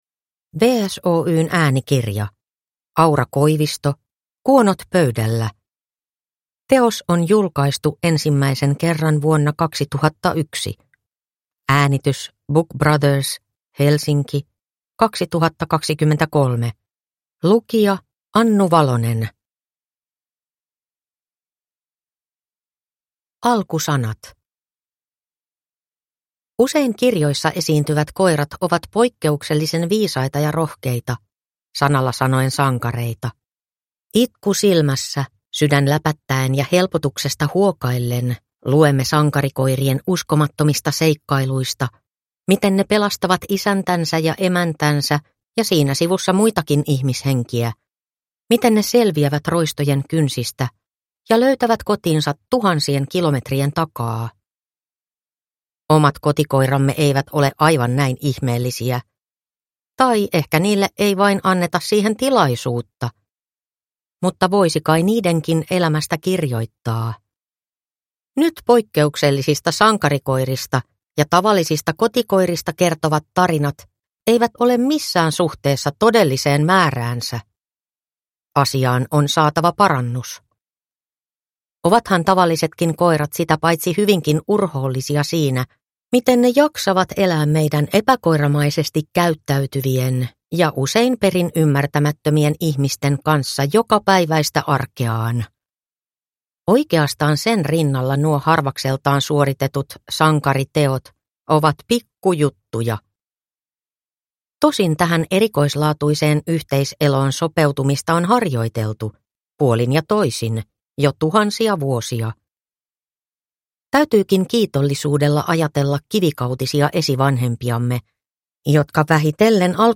Kuonot pöydällä – Ljudbok – Laddas ner